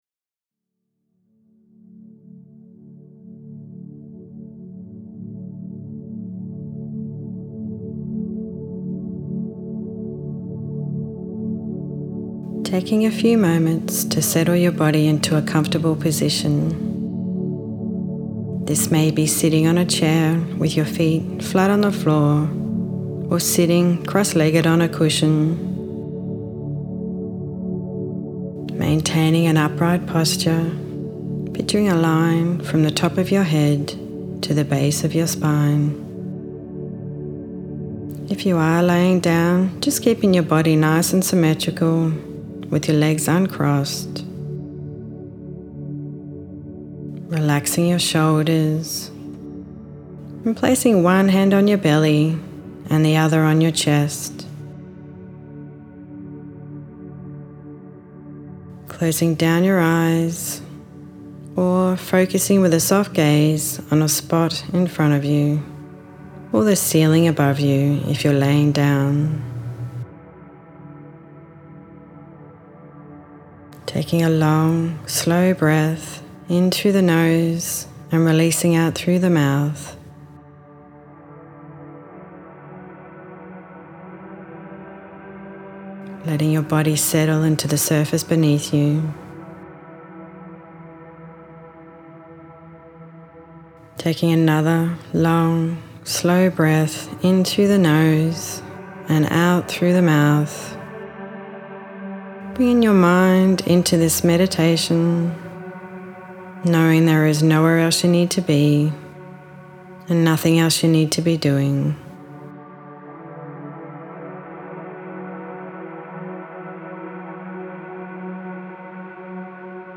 Listen to my free breath meditation